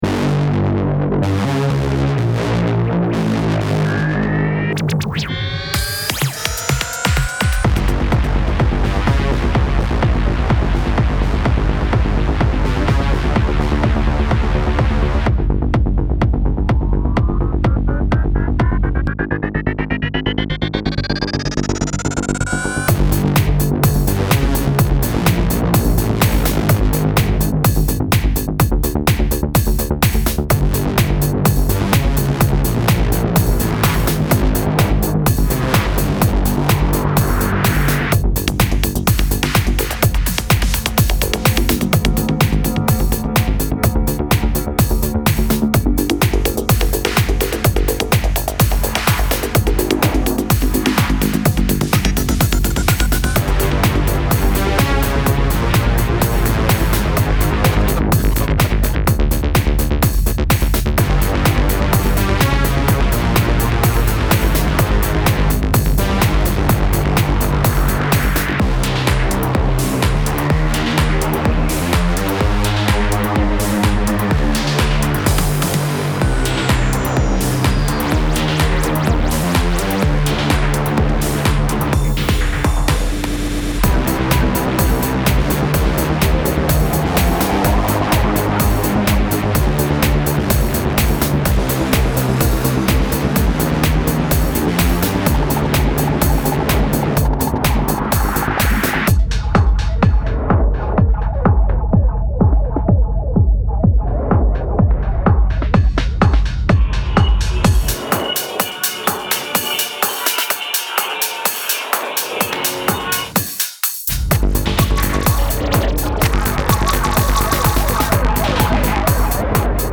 Recently made with PS-20...